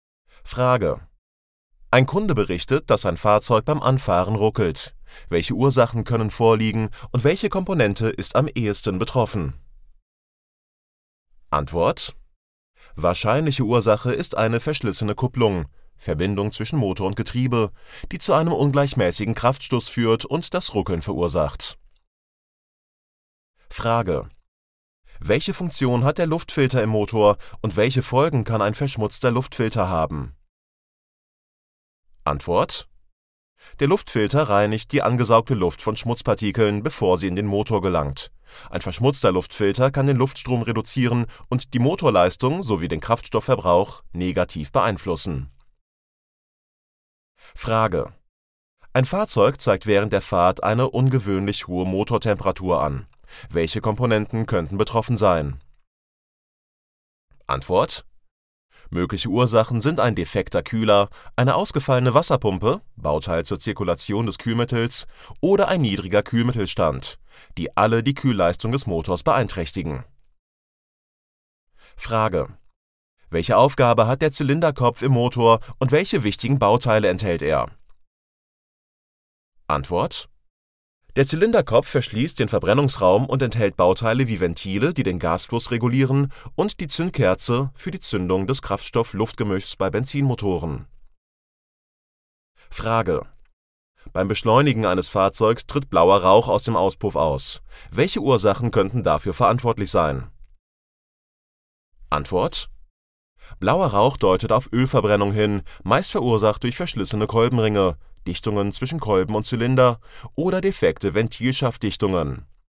MP3 Hörbuch Kraftfahrzeugmechatroniker KFZ - Download
Hörprobe KFZ